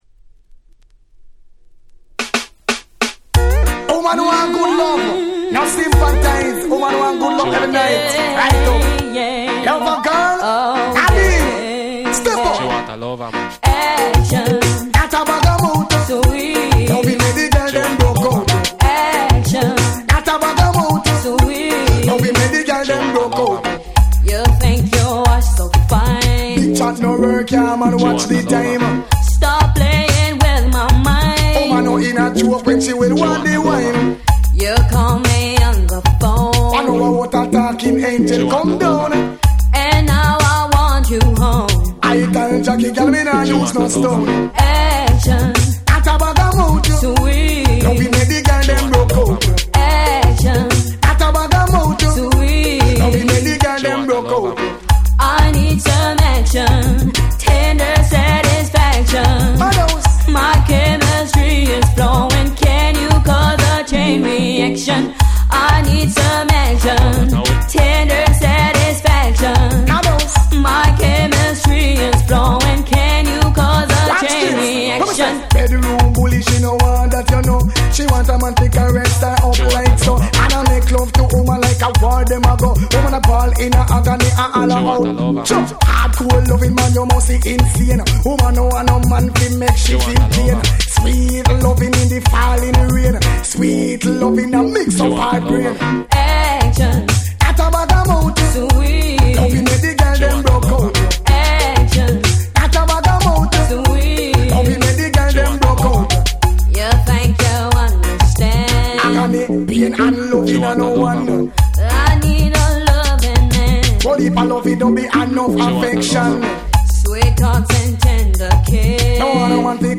94' Super Dancehall Reggae Classic !!
女性Vocalも絡むキャッチーな1曲！